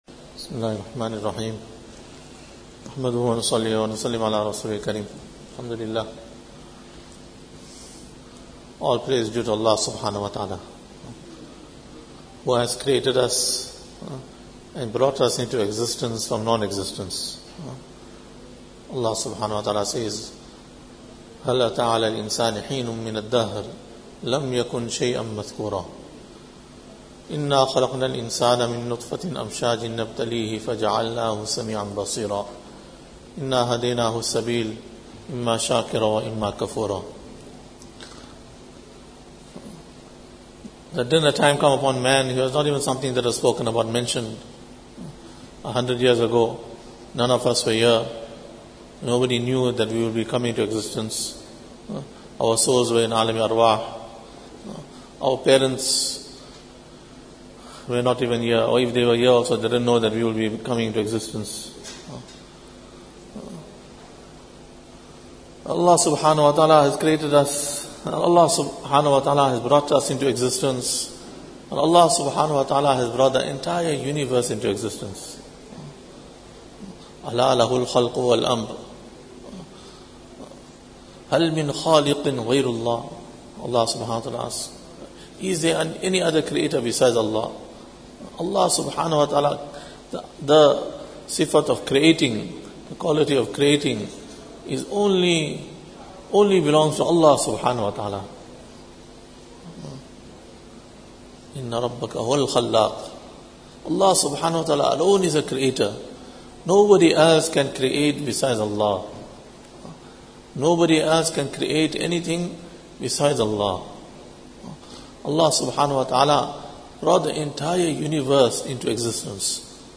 After Maghrib Bayaan